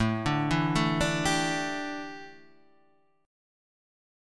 A7sus4 chord